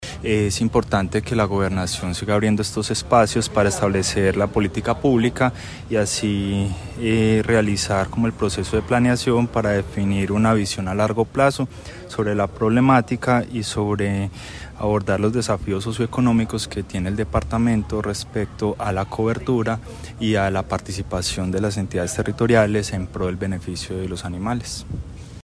El evento inaugural, realizado en el Concejo Municipal de Dosquebradas, reunió a representantes de la comunidad, expertos en bienestar animal, gremios productivos y entidades del Estado, marcando el inicio de una serie de mesas participativas que se llevarán a cabo en todos los municipios del departamento.